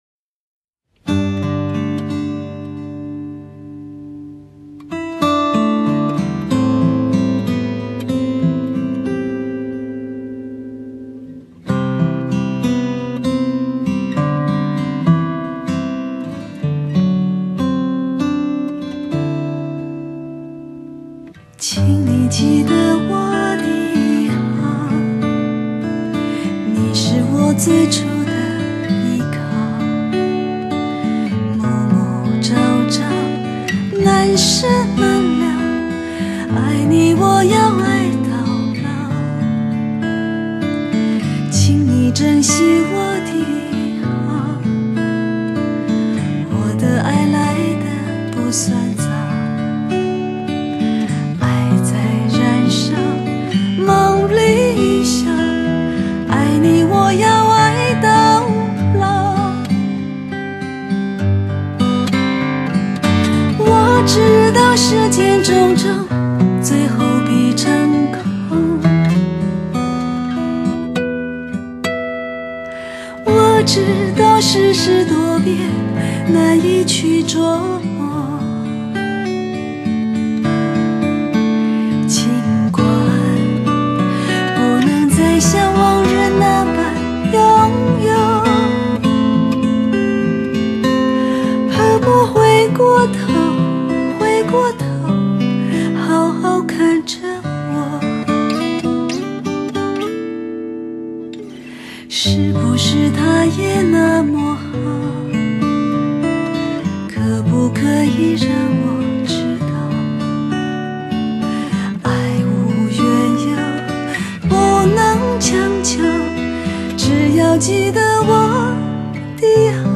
延伸觸角至「臺灣情」及「中國風」兩種元素
這一次她用最貼近的心情及精致的嗓音，